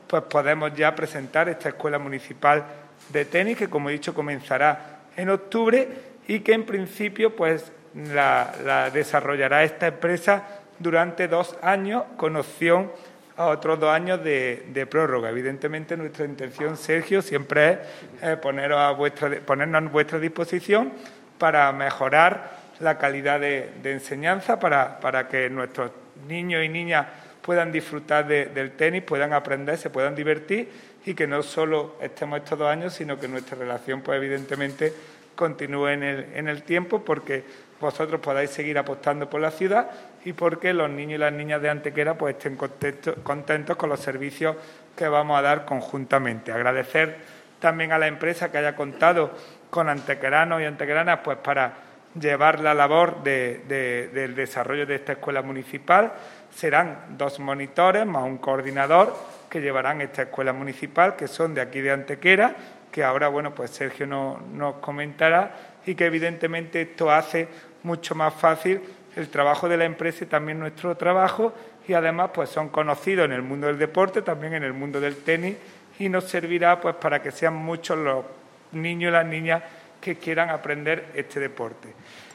El teniente de alcalde delegado de Deportes, Juan Rosas, ha presentado en rueda de prensa la puesta en marcha de otra escuela deportiva municipal de cara a la nueva temporada deportiva 2021-2022.
Cortes de voz